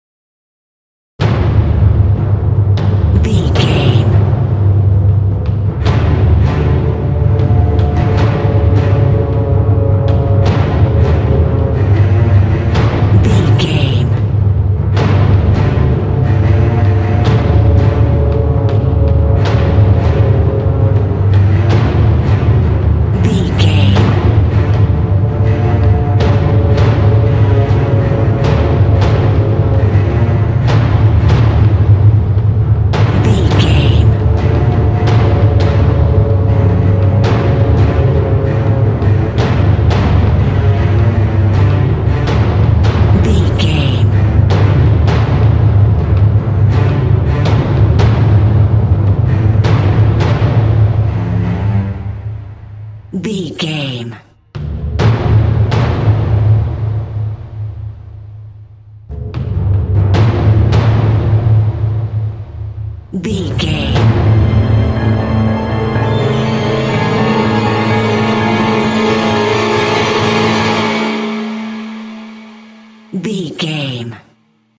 Suspense Music With a Big Ending.
In-crescendo
Thriller
Aeolian/Minor
ominous
haunting
eerie
percussion
synthesizer
taiko drums
timpani